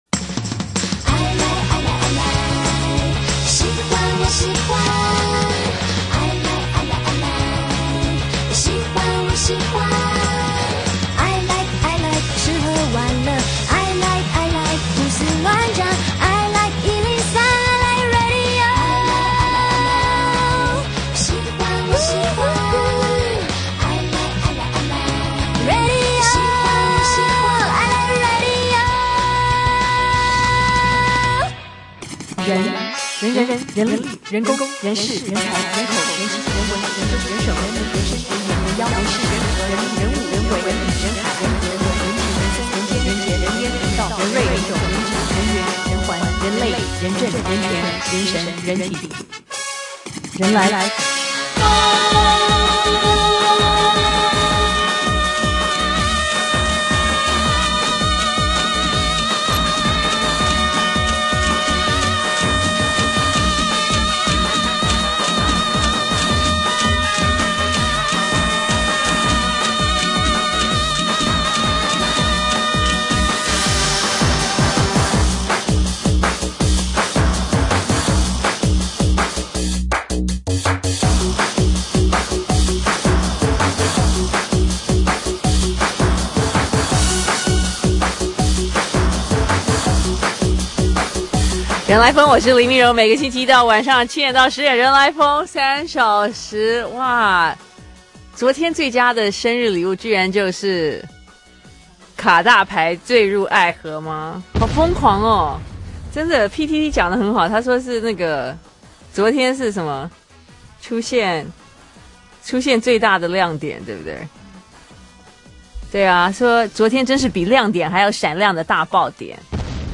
2012-10-23星期二（專訪 胡晴舫-第三人）